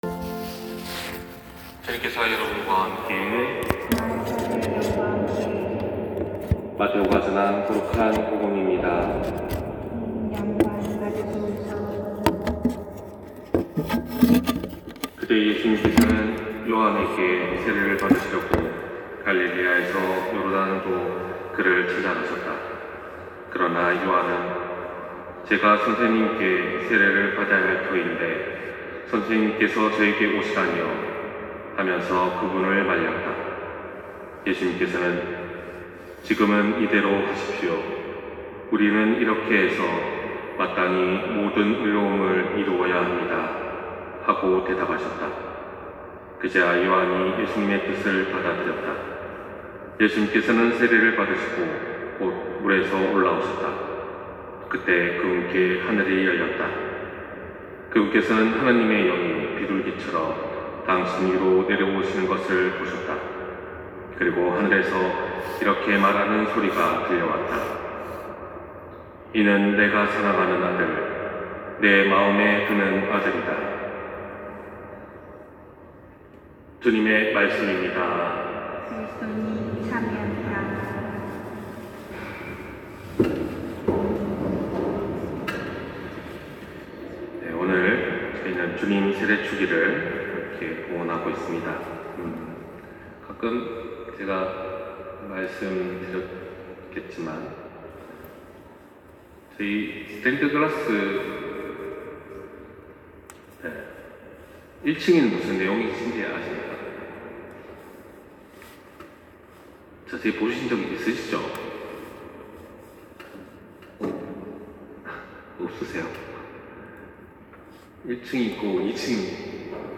260110 신부님 강론말씀